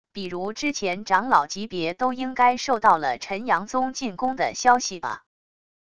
比如之前长老级别都应该受到了辰阳宗进攻的消息吧wav音频生成系统WAV Audio Player